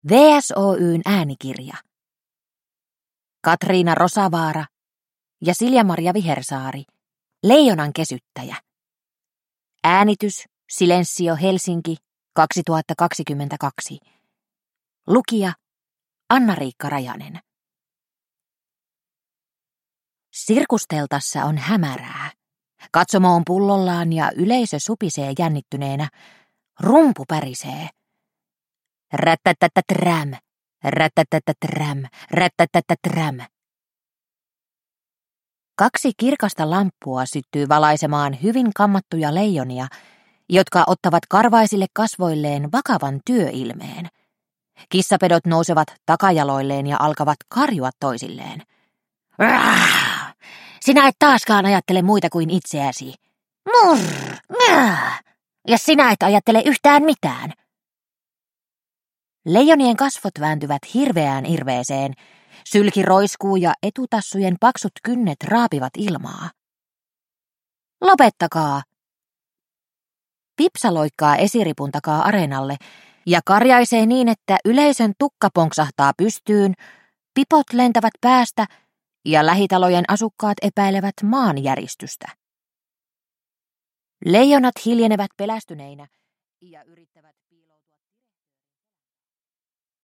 Leijonankesyttäjä – Ljudbok – Laddas ner